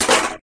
Stul Upal.ogg